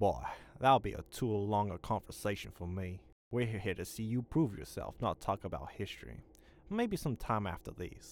Boy that wold be a too long a conversation for me.wav